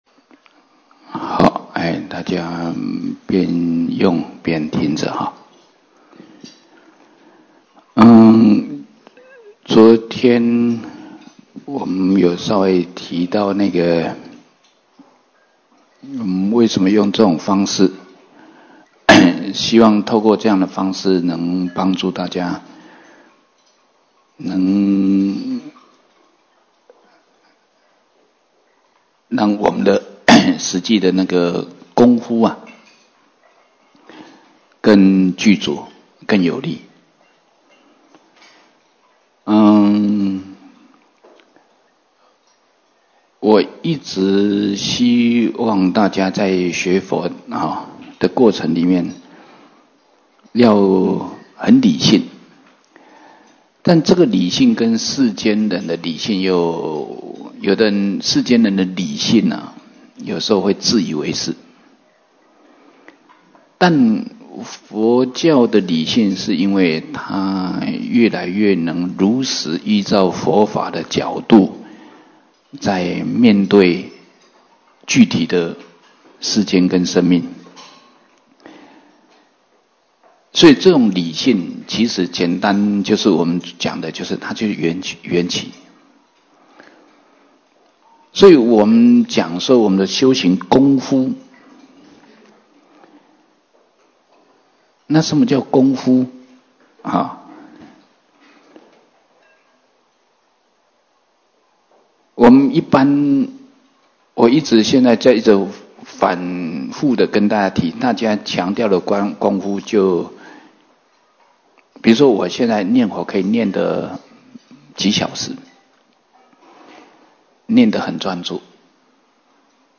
34佛七开示